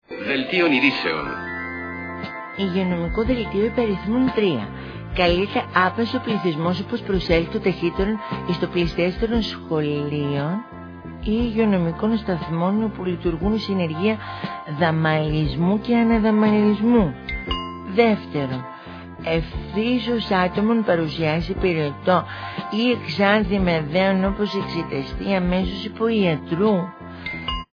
Ηχητική μπάντα παράστασης
sound 27'', track 34, ηχητικά εφέ (σήμα σταθμού και δελτίο ειδήσεων)